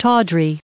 799_tawdry.ogg